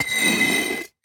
grindstone3.ogg